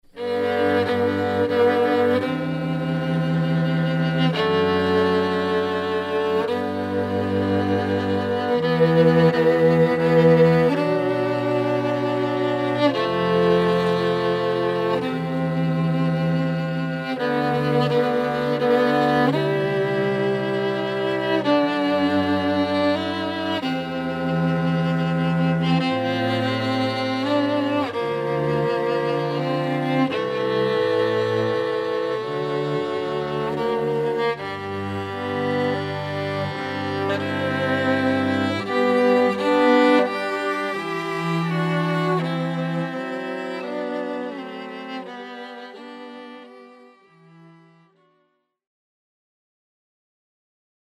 (Violin, Viola, Cello)